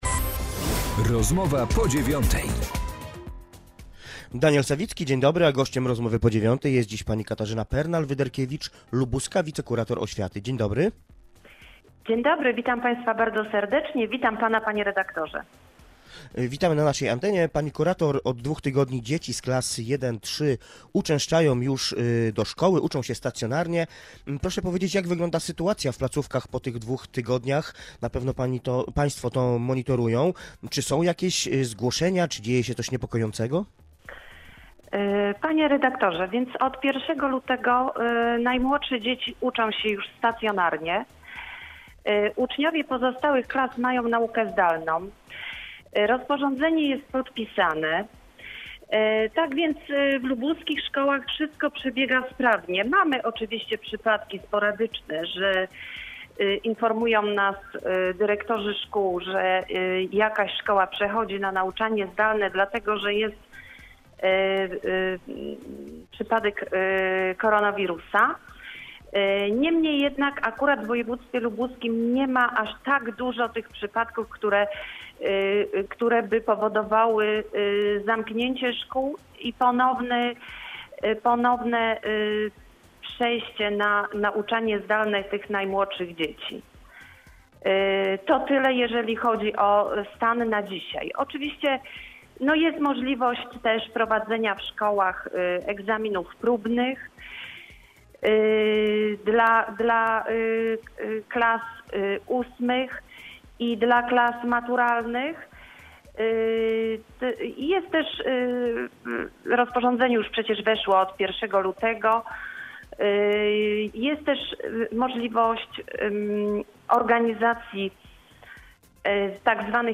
Z lubuską wicekurator oświaty rozmawia